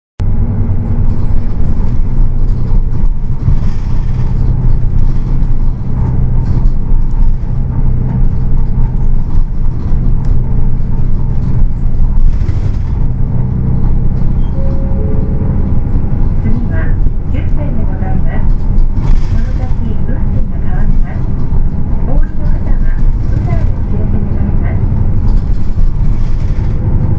音声合成装置 指月電機製作所